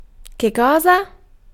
Ääntäminen
Ääntäminen France: IPA: [kɛl] Haettu sana löytyi näillä lähdekielillä: ranska Käännös Ääninäyte Pronominit 1. che {m} Muut/tuntemattomat 2. che cosa 3. cosa {f} Suku: m .